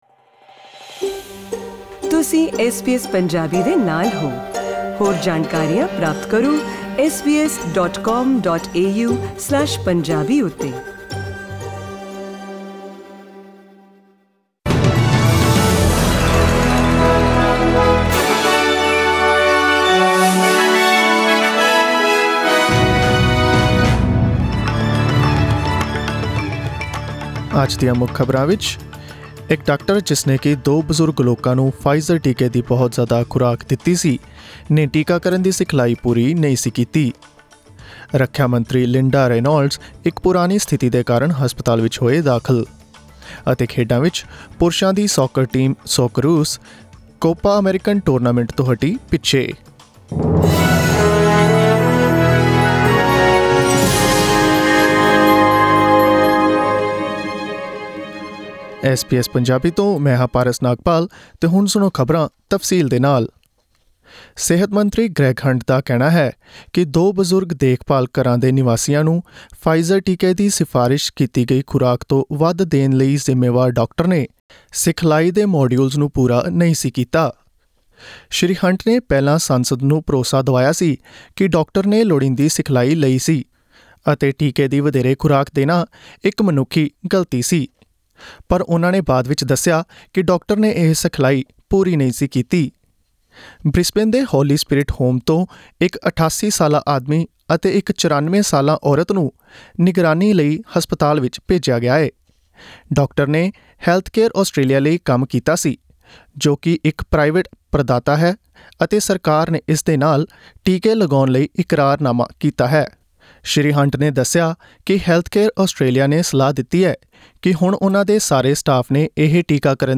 Australian News in Punjabi 24 Feb 2021